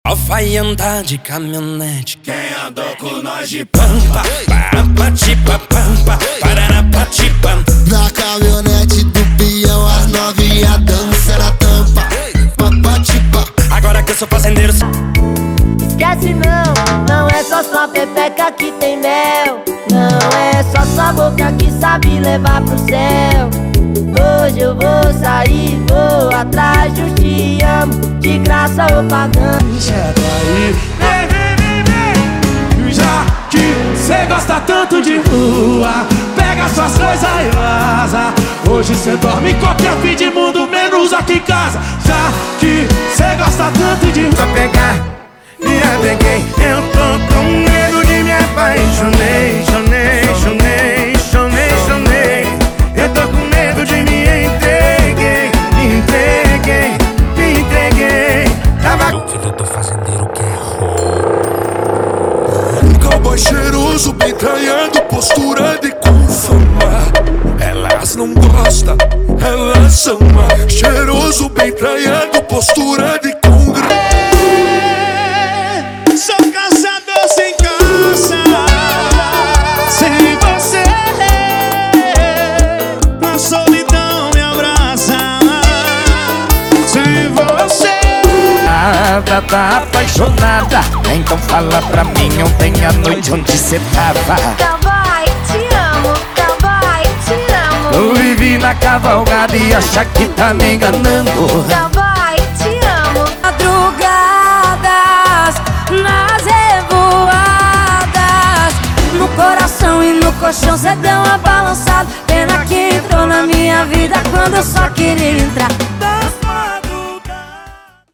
• Sem Vinhetas
• Em Alta Qualidade